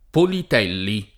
Politelli [ polit $ lli ] cogn.